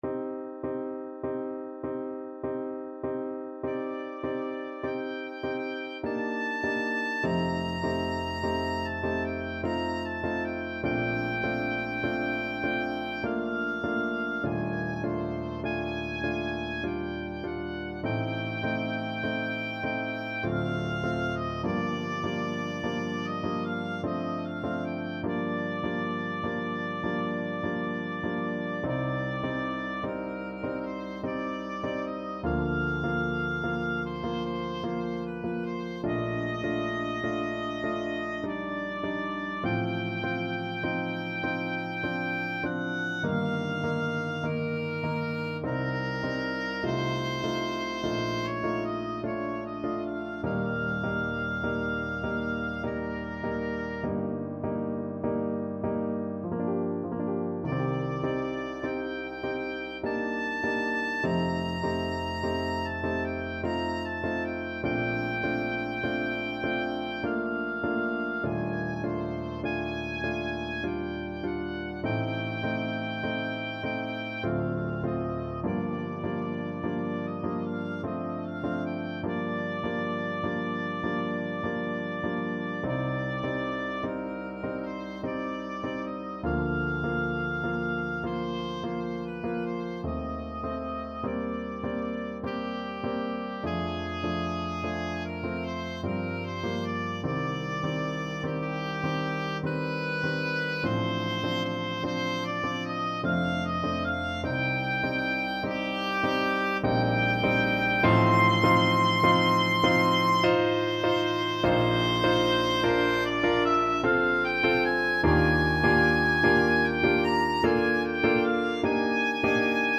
Oboe
Bb major (Sounding Pitch) (View more Bb major Music for Oboe )
3/4 (View more 3/4 Music)
Andantino = 50 (View more music marked Andantino)
Classical (View more Classical Oboe Music)